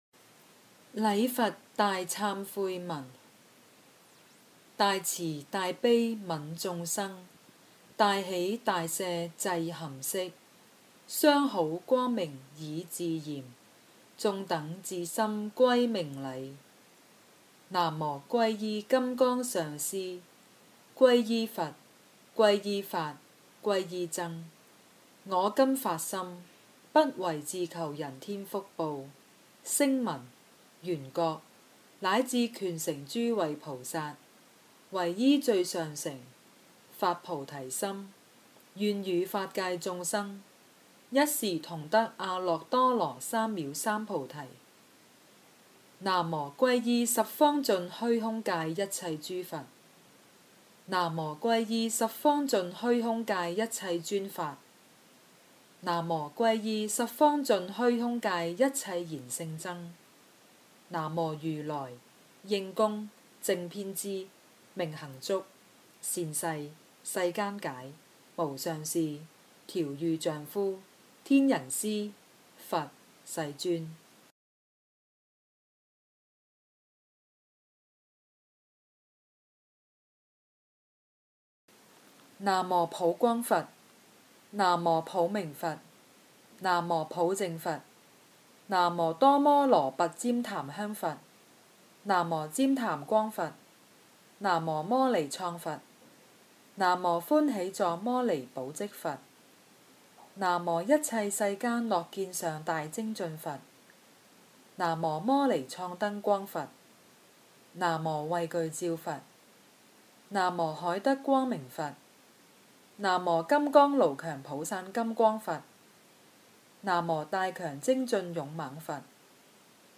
《礼佛大忏悔文》经文教念粤语版